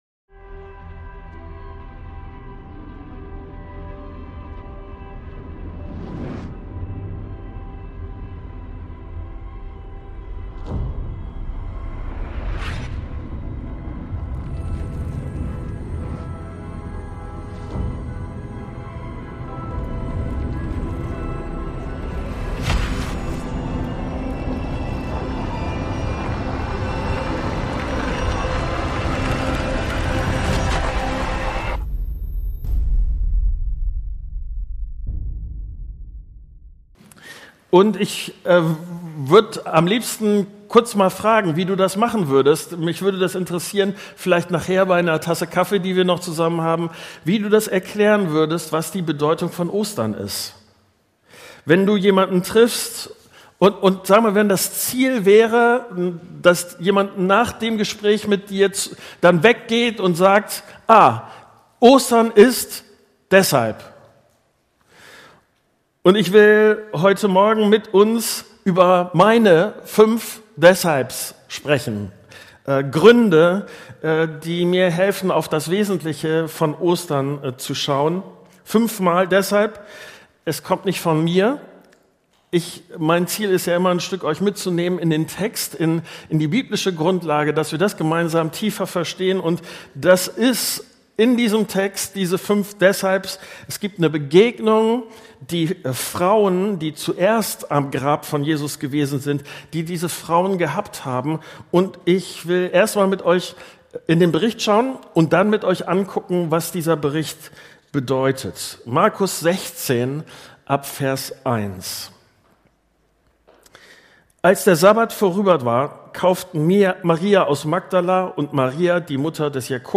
Serie: Standortgottesdienste